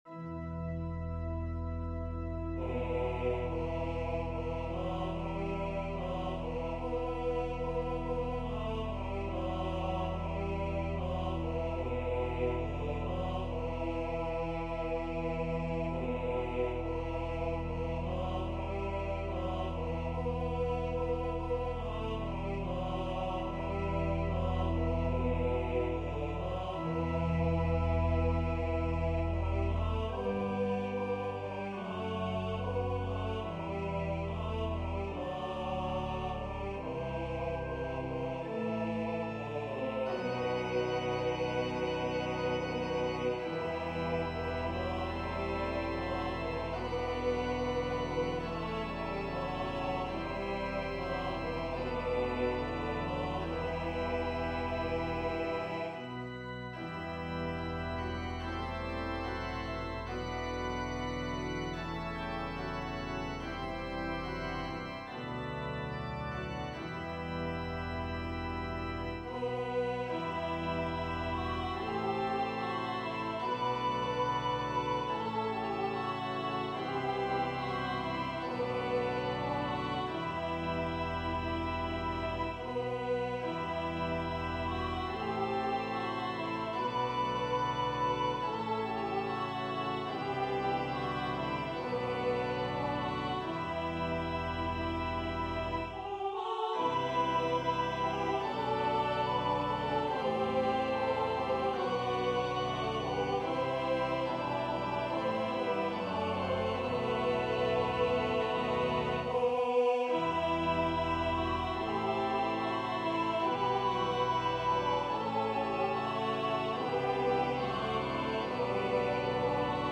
Voicing/Instrumentation: SATB , Organ/Organ Accompaniment We also have other 6 arrangements of " His Voice as the Sound ".